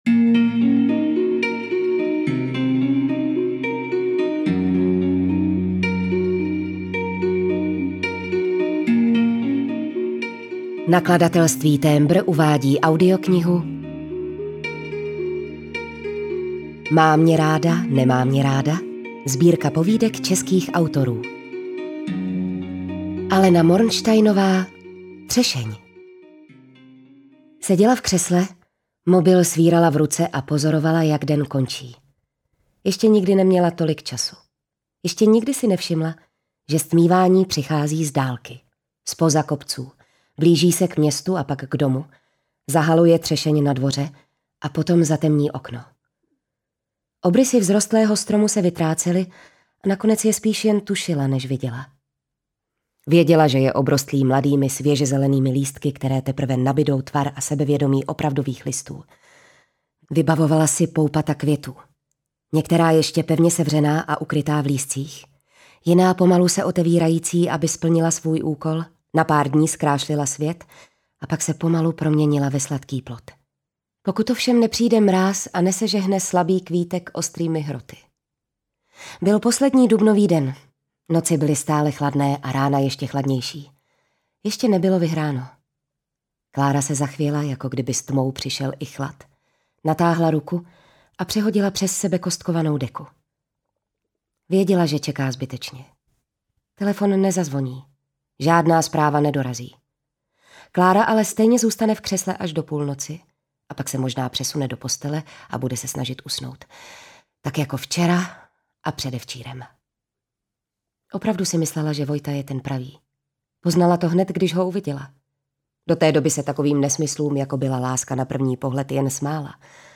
Má mě ráda, nemá mě ráda - Alena Mornštajnová, Michal Viewegh, Aňa Gaislerová, Petra Dvořáková, Jaroslav Rudiš, Alice Nellis, Bianca Bellová, Anna Bolavá, Boris Dočekal, Irena Hejdová - Audiokniha